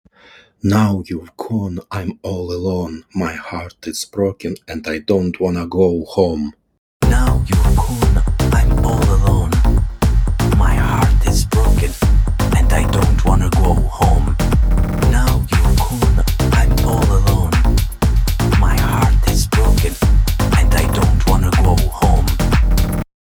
Удивительно, что много обсуждений на форуме применения нейросетей в музыке, но здесь никто не отметился (пример от ТС, исходное аудио в микрофон ноутбука в начале примера) Вложения Project_test.mp3 Project_test.mp3 915,2 KB · Просмотры: 997